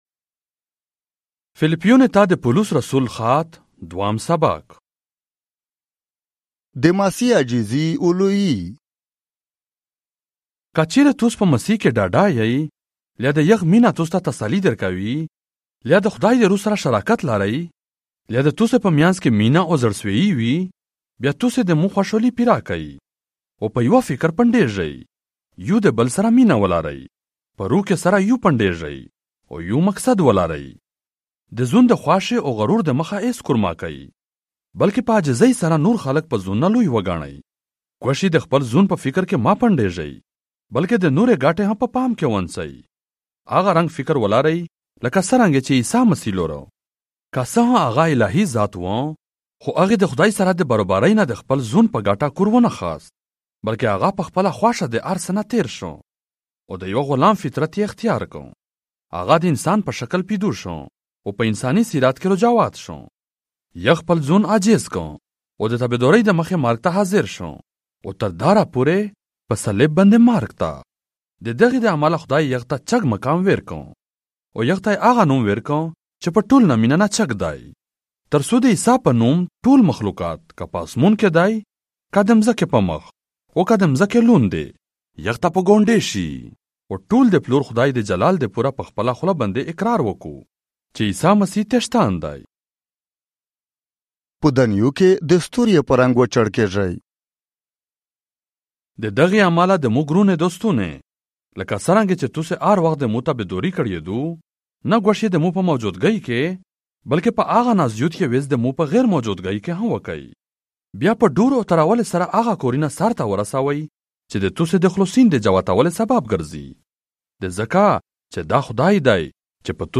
Philippians - Chapter 2 in the Pashto language, Central - audio 2025